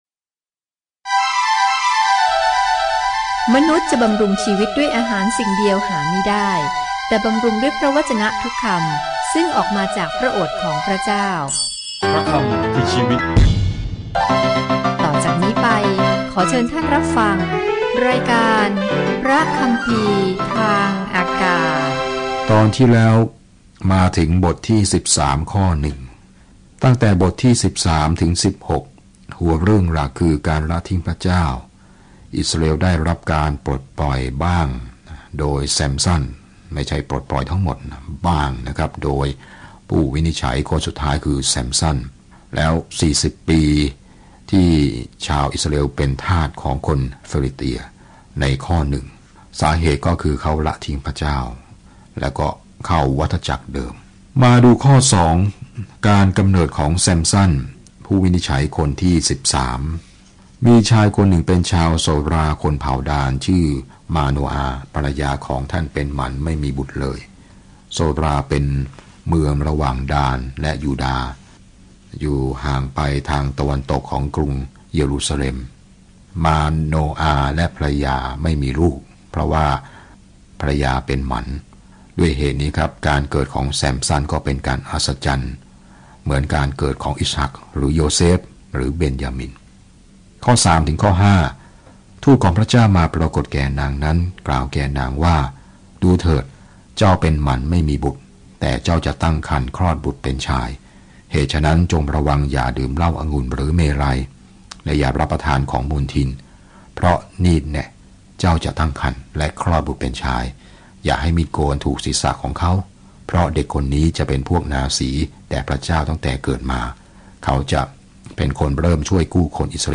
ผู้พิพากษาบันทึกชีวิตที่พลิกผันและกลับหัวกลับหางของผู้คนที่กำลังเริ่มต้นชีวิตใหม่ในอิสราเอล เดินทางทุกวันผ่าน Judges ในขณะที่คุณฟังการศึกษาด้วยเสียงและอ่านข้อที่เลือกจากพระวจนะของพระเจ้า